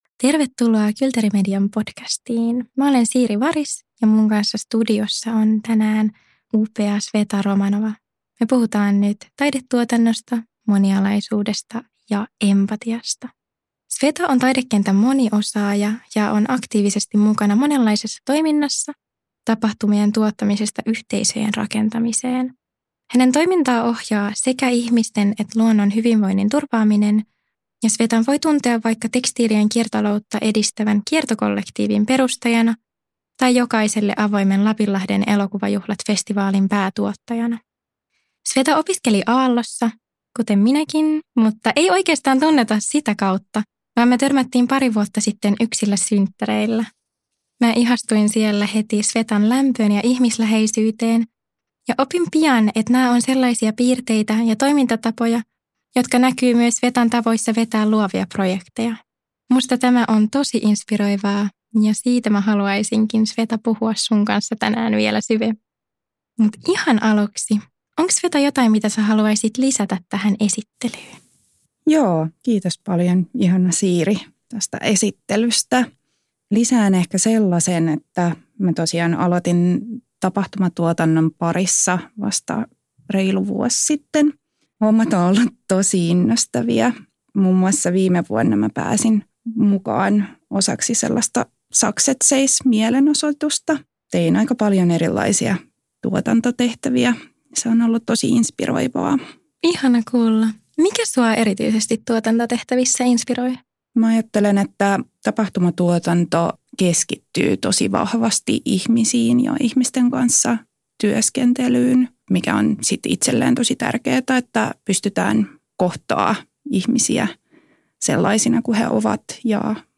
Business students studying Sustainable Entrepreneurship at Aalto discuss their experiences with entrepreneurship before and after joining their master’s programme, as well as their entrepreneurial endeavours in the future. What are the pros and cons of becoming an entrepreneur?